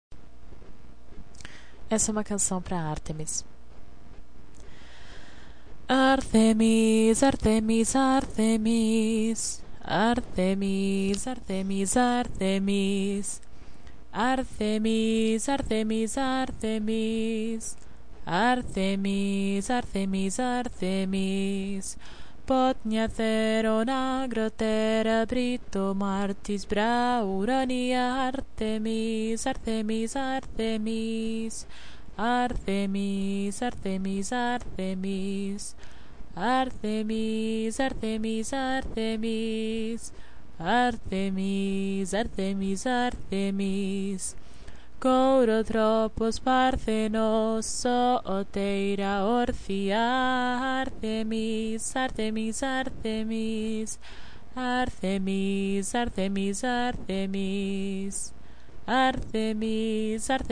I share it with you and I hope you use it as much as you can, its a very invocative song, but I think it’s mostly to serve as a background ritual song or as a mantra to connect with Her.
I’m sorry for the abrupt ending of the file. I am really no professional at recording!